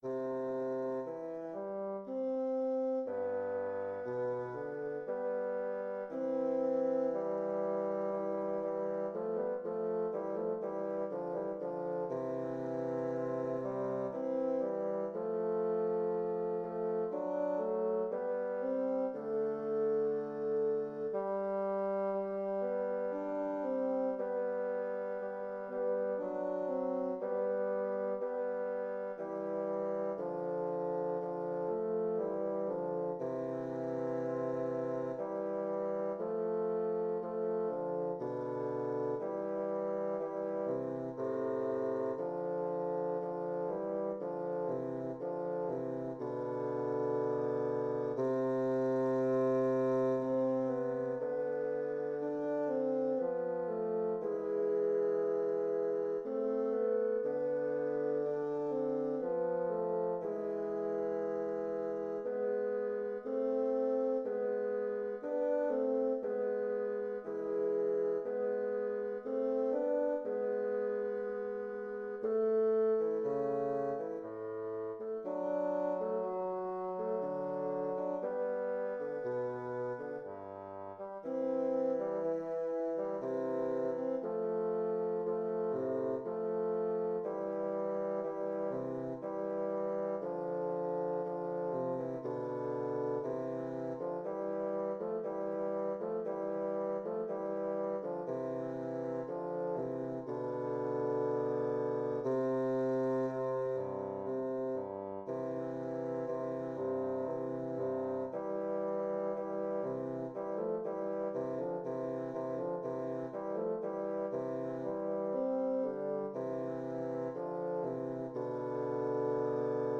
Intermediate bassoon duet
Instrumentation: Bassoon duet
tags: bassoon music